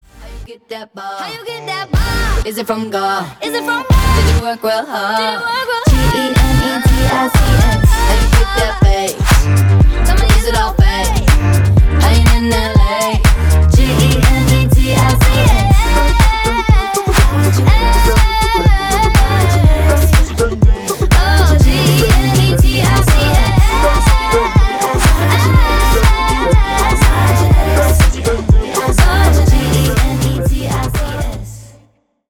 • Качество: 320, Stereo
заводные
dance
Dance Pop
красивый женский голос